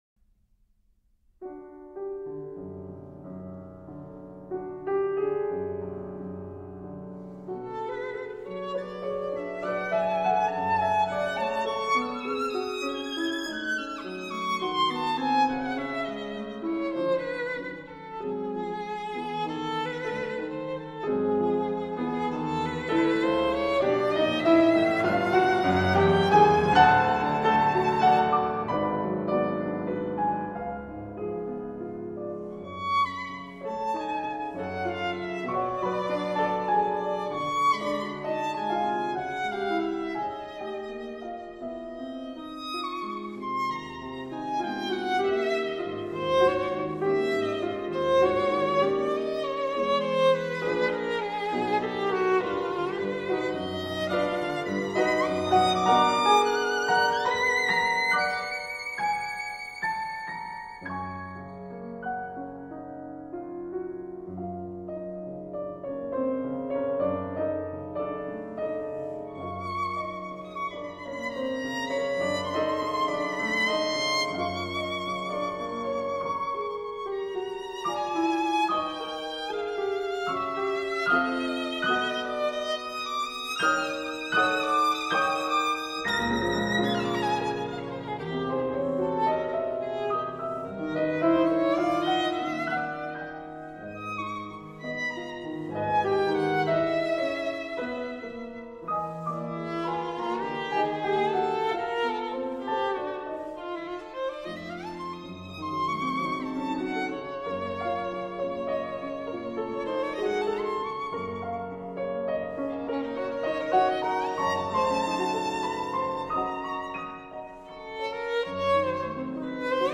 Sonata for Violin and Piano in d minor